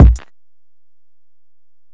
Kick (14).wav